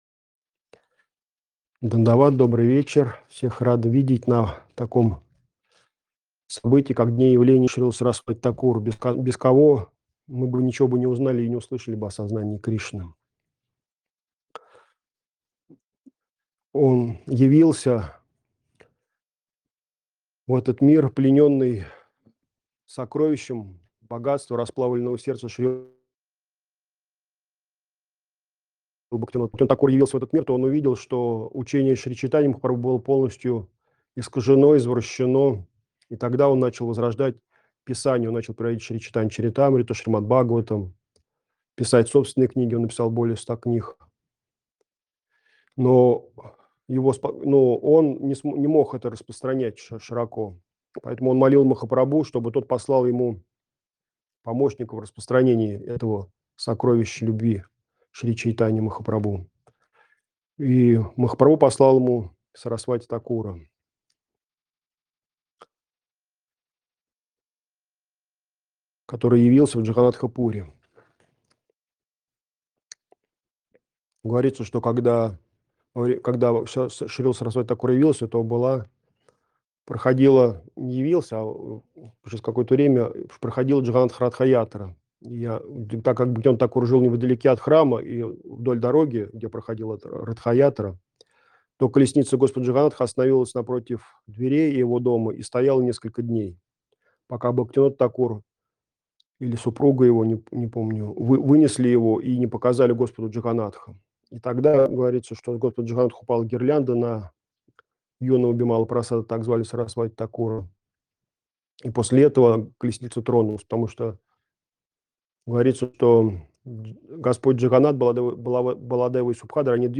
Бхаджан
Проповедь